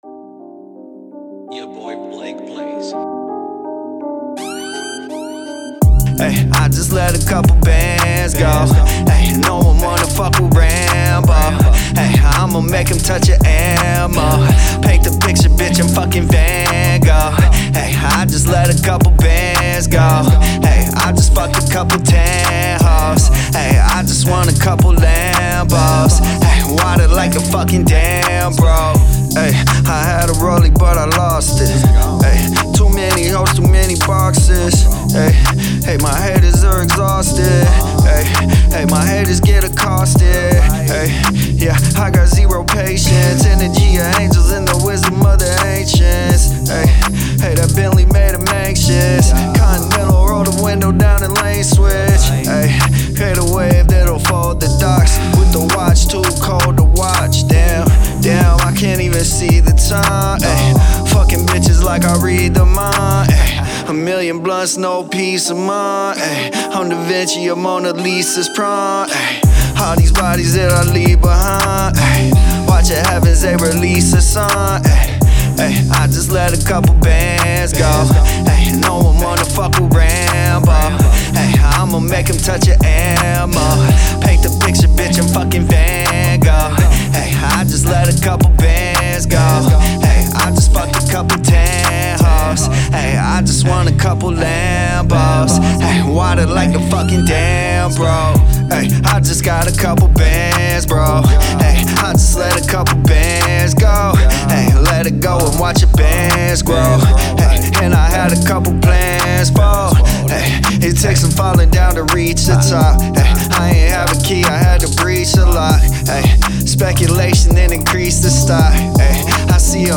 Hiphop
fast-paced, catchy, club banger